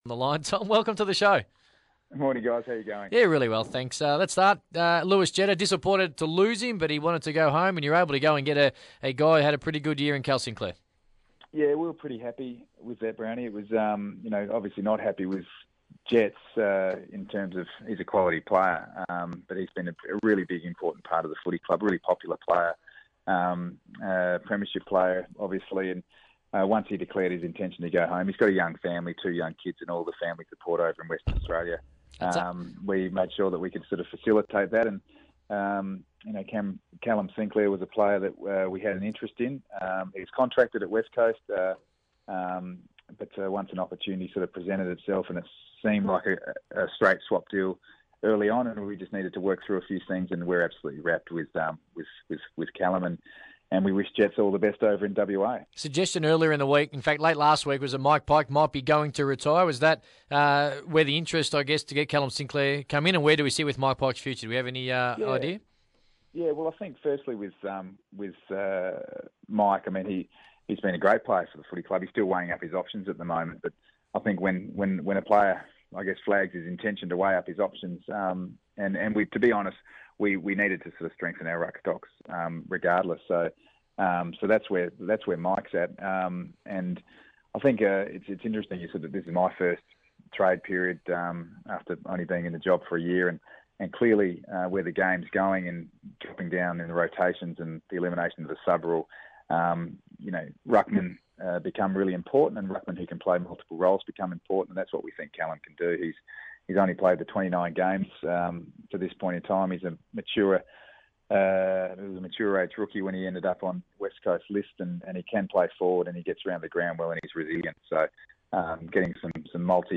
Tom Harley speaks to the team on NAB Trade Radio after the club completed its first official trade of the 2015 period.